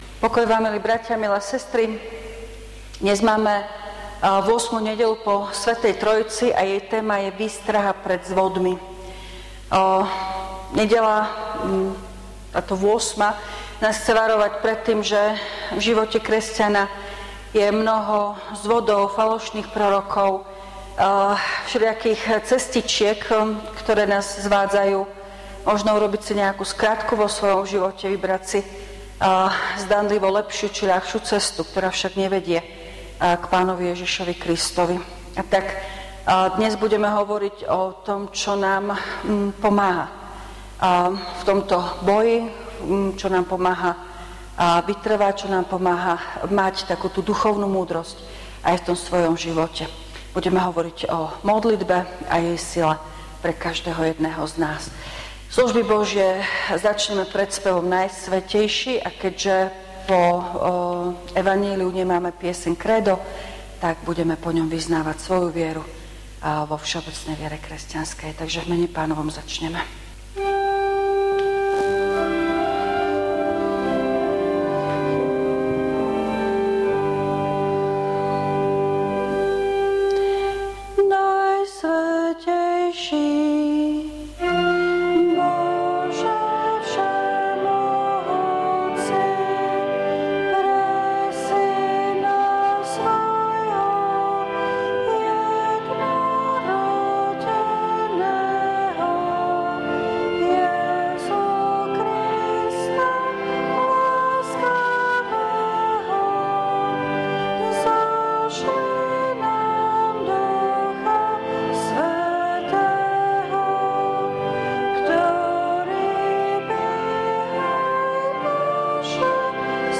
V nasledovnom článku si môžete vypočuť zvukový záznam zo služieb Božích – 8. nedeľa po Sv. Trojici.
Piesne: 188, 318, 313, 652, A49.